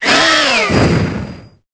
Cri de Matoufeu dans Pokémon Épée et Bouclier.